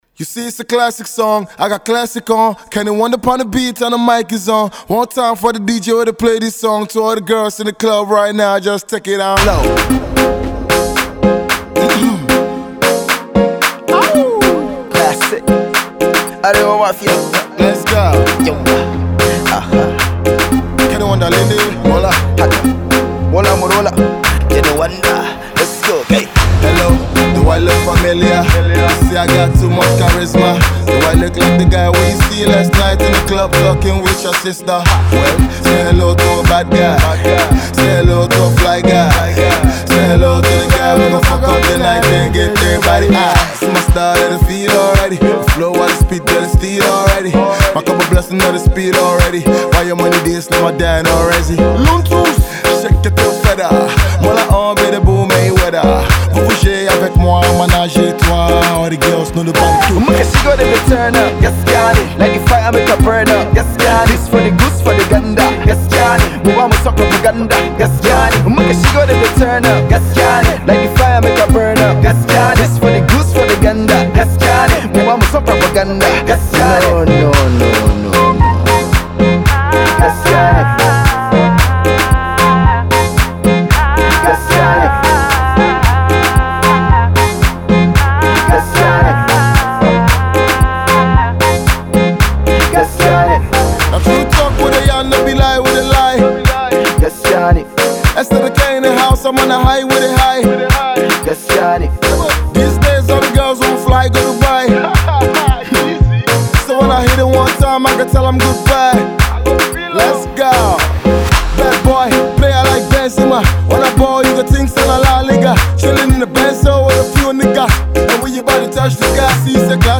and is a unique blend of afro beats and rap.